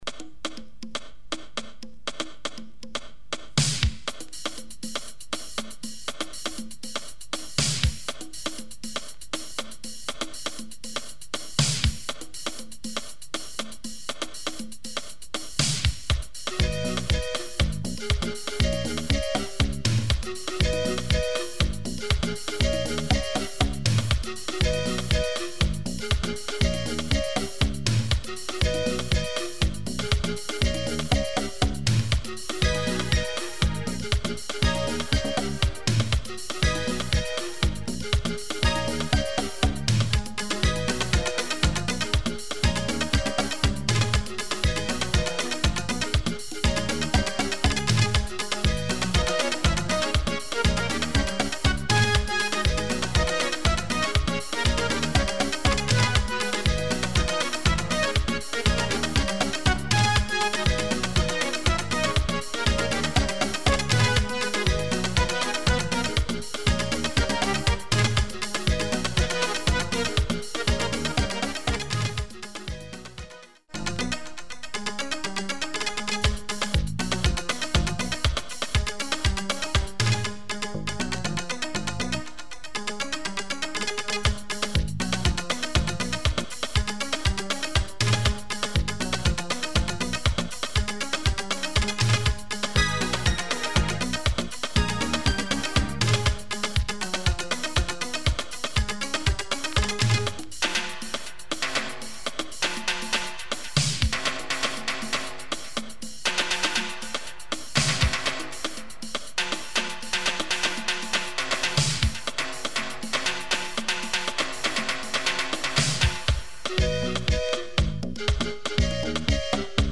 Plus some afro oriented West Indian tunes.